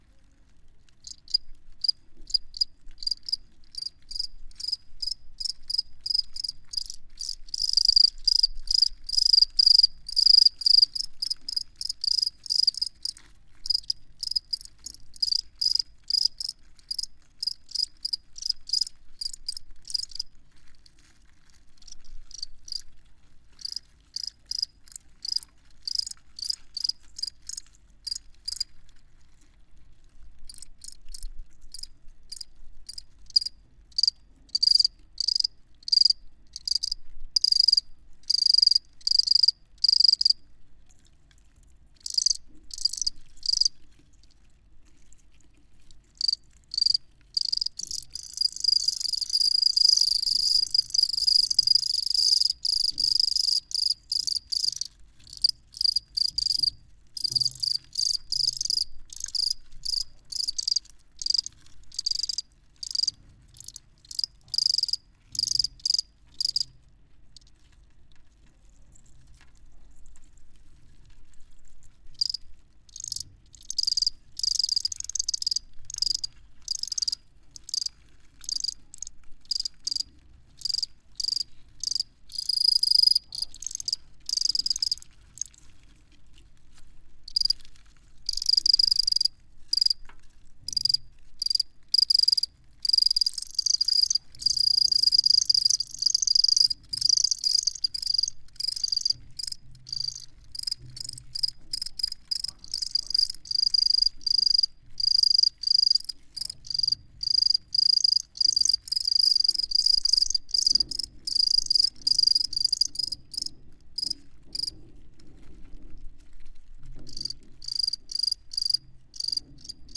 crickets_02.ogg